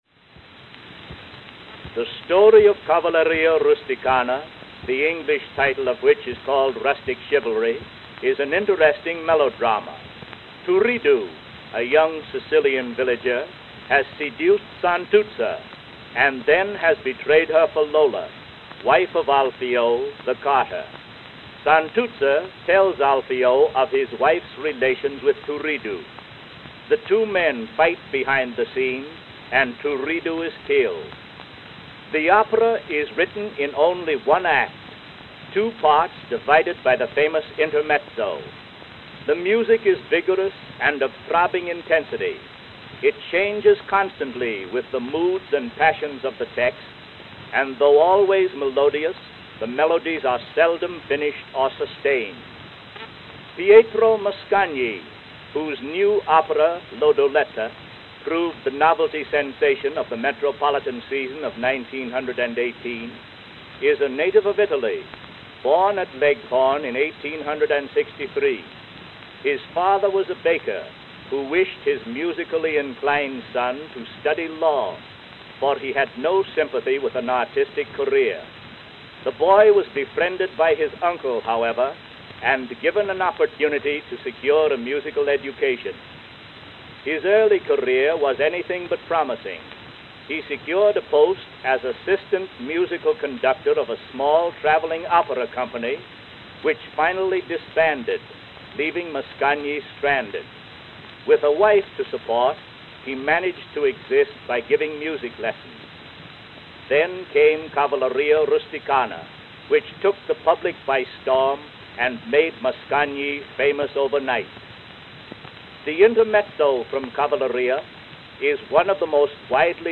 The L-Side contained a spoken description of the song and artist.